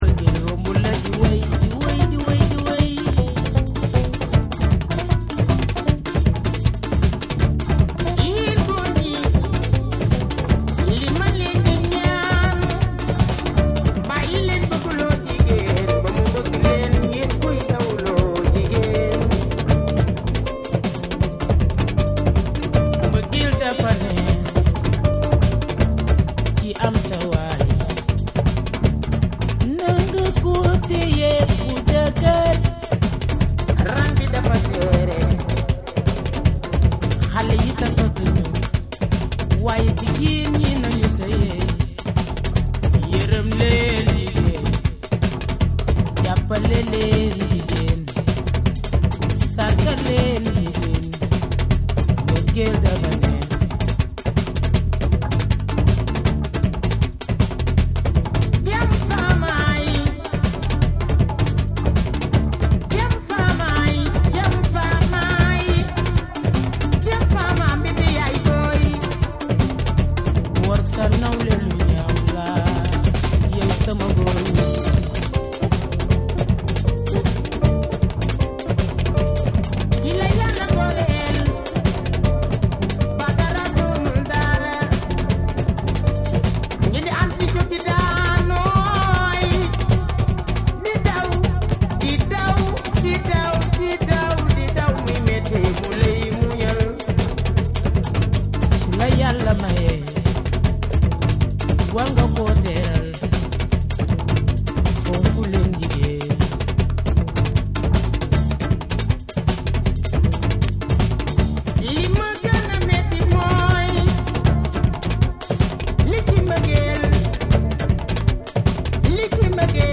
エネルギッシュなパーカッションと深みのあるベースが生み出すグルーヴが圧倒！
WORLD